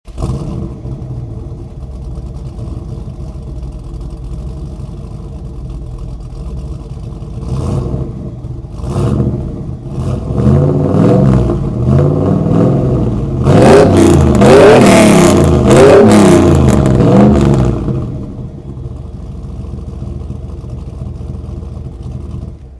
Глубокий звук мотора BMW V8